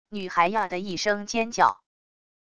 女孩啊的一声尖叫wav音频